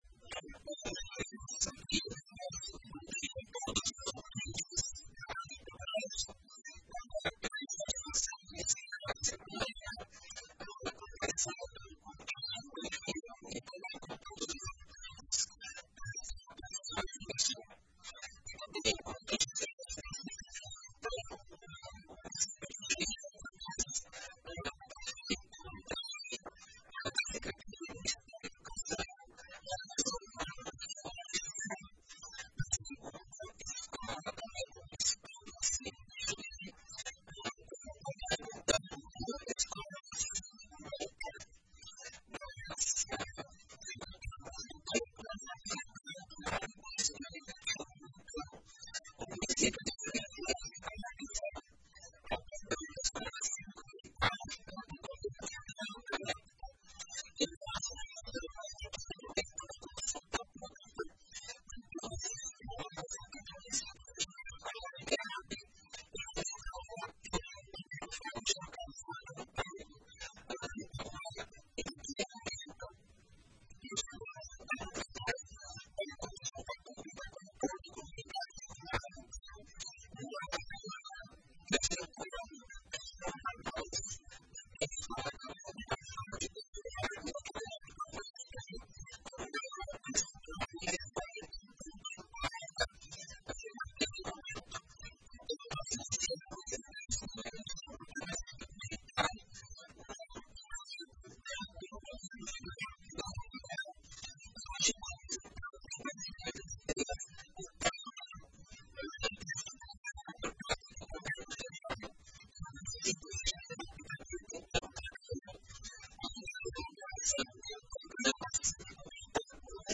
Na entrevista completa disponível abaixo, Cláudio de Souza explicou quais são os próximos passos em relação a implantação do programa.
Secretario-de-Educacao-Claudio-Souza.mp3